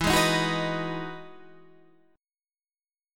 EM9 Chord
Listen to EM9 strummed